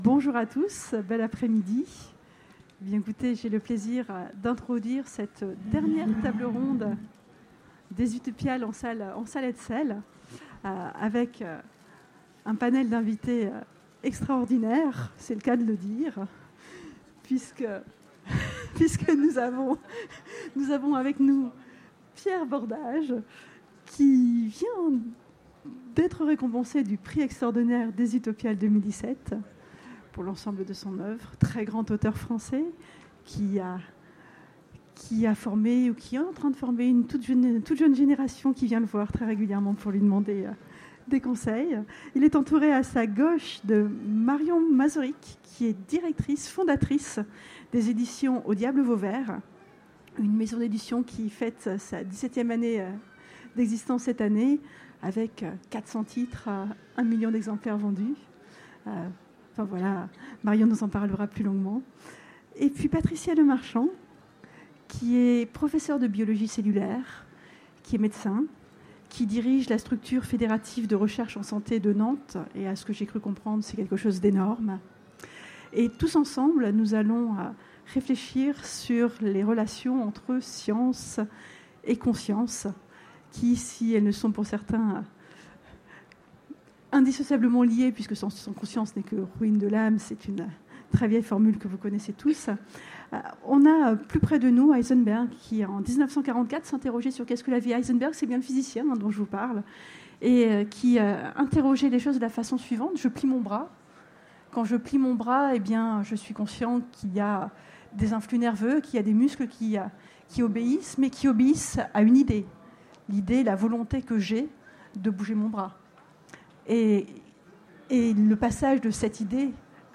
Utopiales 2017 : Conférence Science(s) et conscience(s)